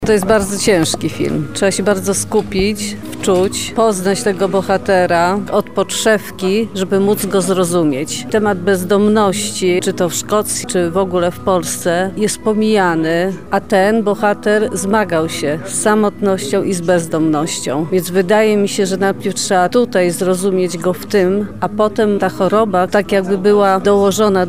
Wrażenia uczestników